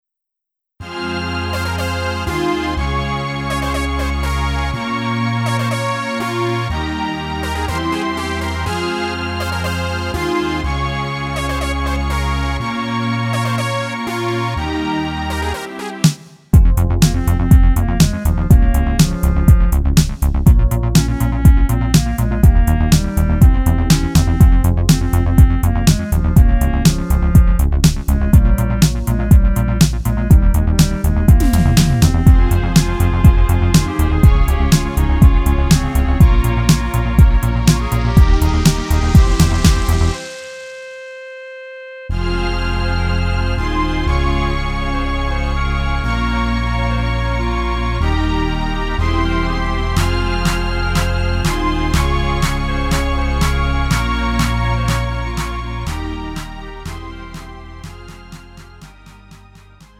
축가, 웨딩, 결혼식 MR. 원하는 MR 즉시 다운로드 가능.
음정 원키
장르 가요